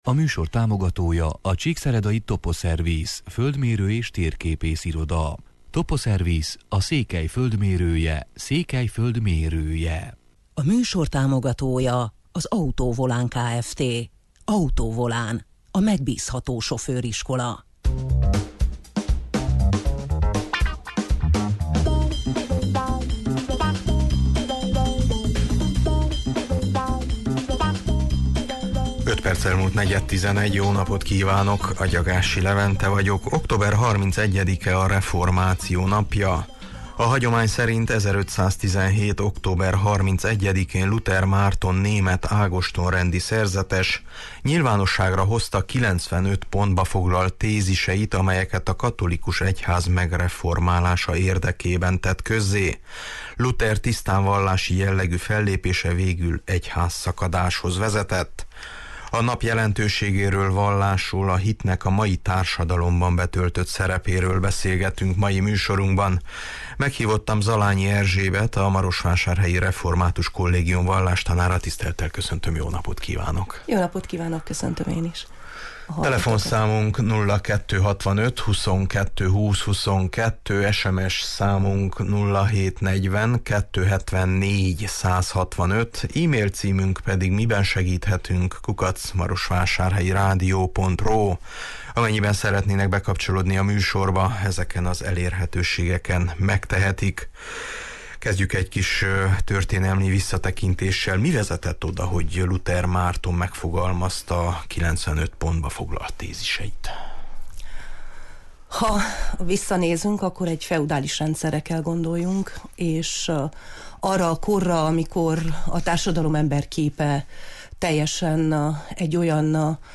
A nap jelentőségéről, vallásról, a hitnek a mai társadalomban betöltött szerepéről beszélgetünk mai műsorunkban.